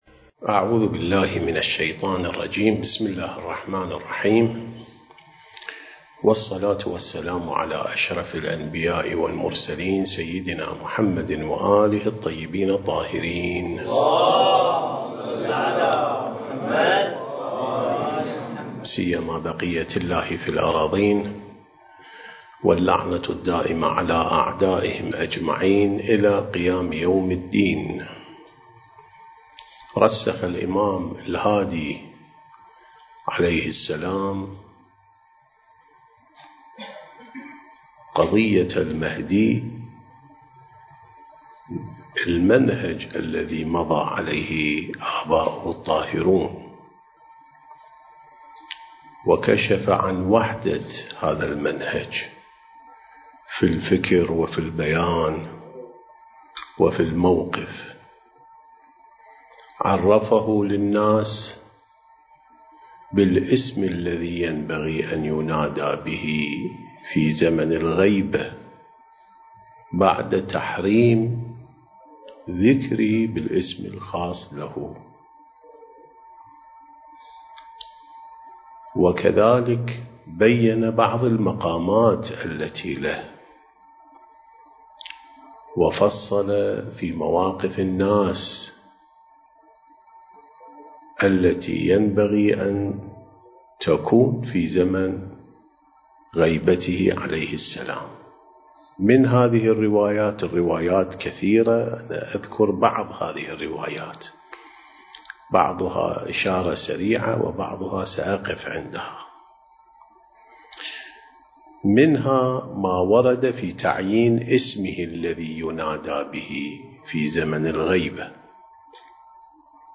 سلسلة محاضرات: الإعداد الربّاني للغيبة والظهور (11)